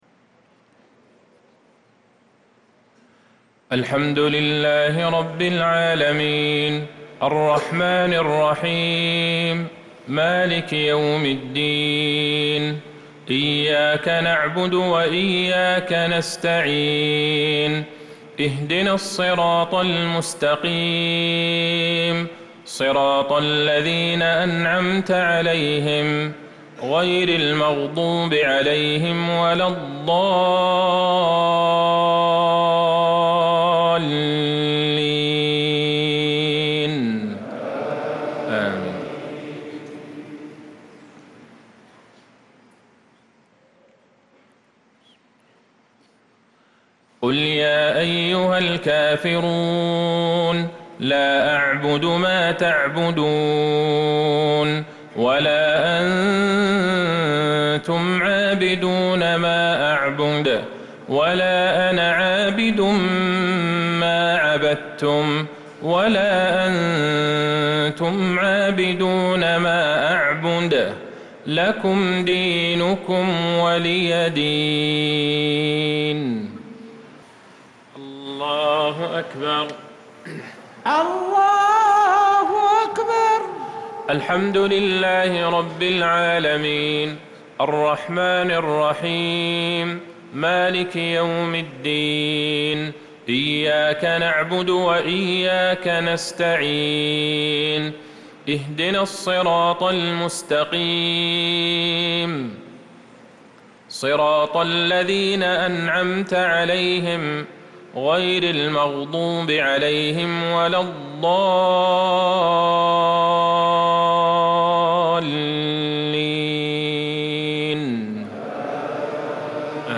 صلاة المغرب للقارئ عبدالله البعيجان 21 ذو الحجة 1445 هـ
تِلَاوَات الْحَرَمَيْن .